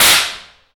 Index of /90_sSampleCDs/Roland L-CD701/PRC_Trash+Kitch/PRC_Kitch Tuned